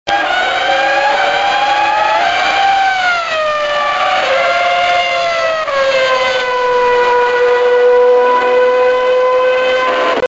Мне нужны звуки гоночных машин!